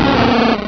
Cri de Magby dans Pokémon Rubis et Saphir.